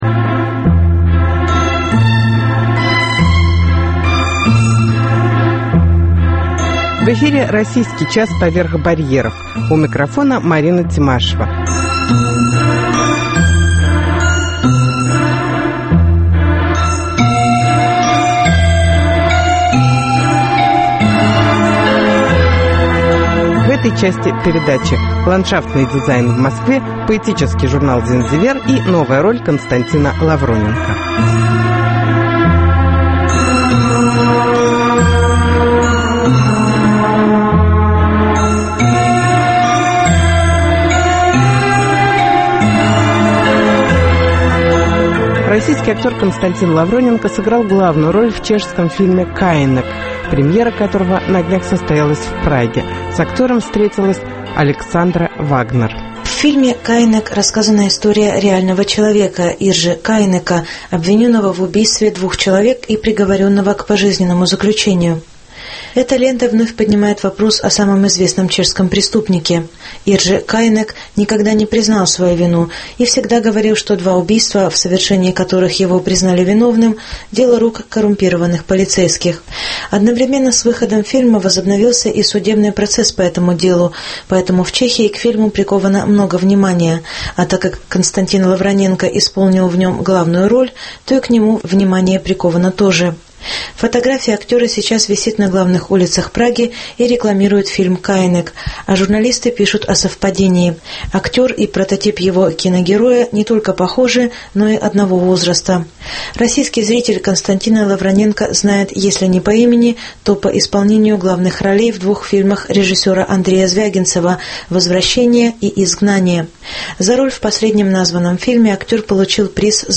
Интервью с актером Константином Лавроненко